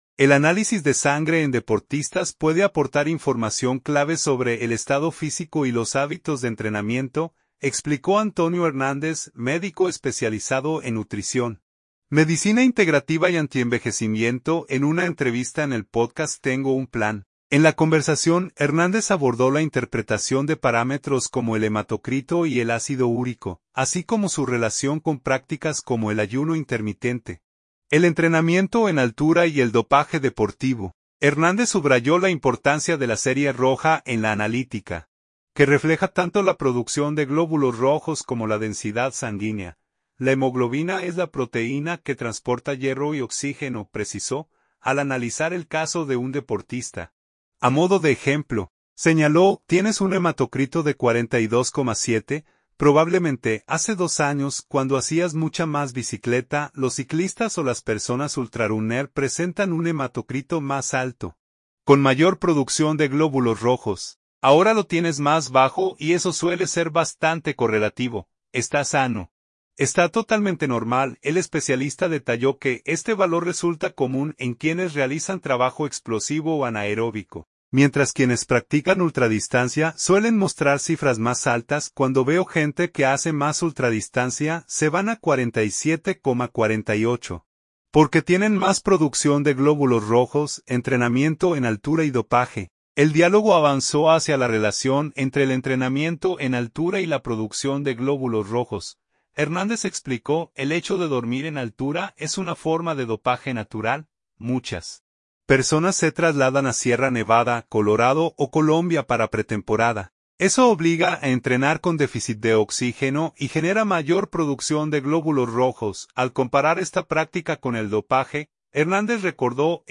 En entrevista con el pódcast Tengo un Plan